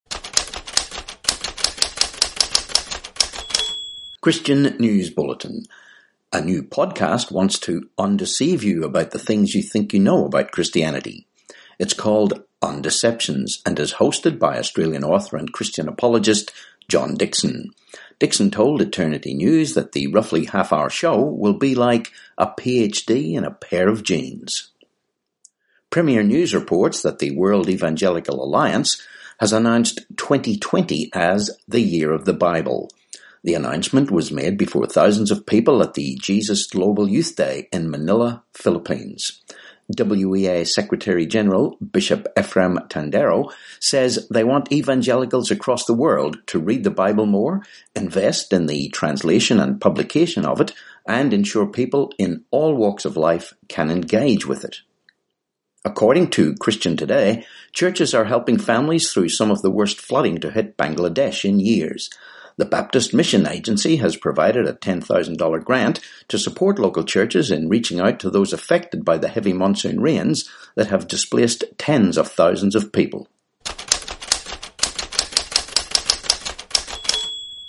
15Sep19 Christian News Bulletin